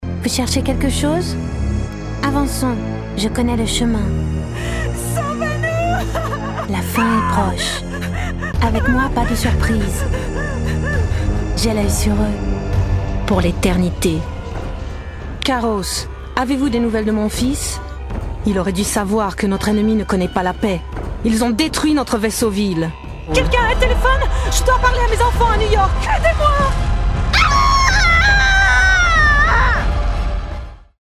franzĂ¶sische Sprecherin.
She own a recording studio of good quality (Neumann microphone, iso-booth, ISDN).
Sprechprobe: eLearning (Muttersprache):
Native female French voice talent.